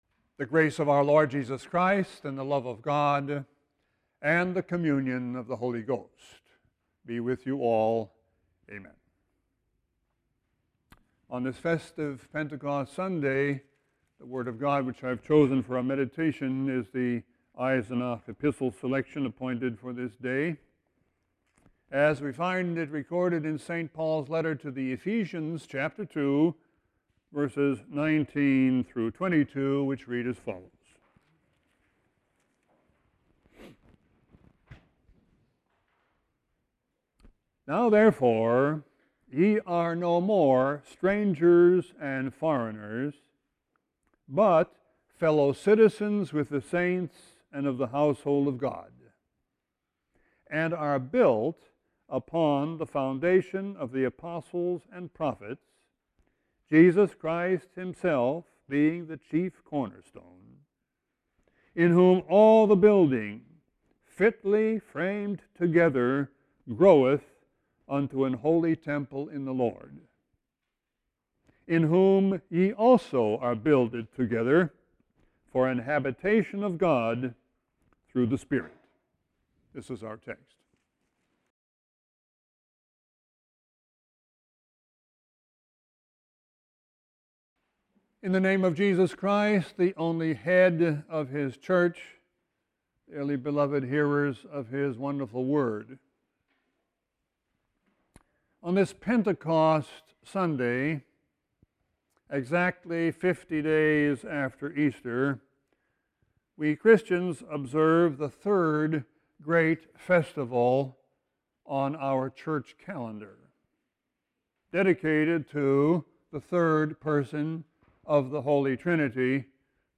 Sermon 5-15-16.mp3